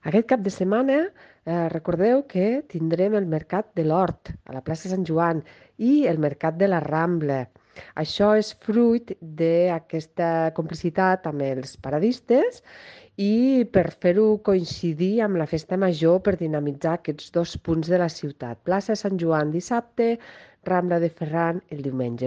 Tall de veu de la regidora Marta Gispert sobre els mercats de l'Hort i de la Rambla que se celebraran aquest cap de setmana de festa major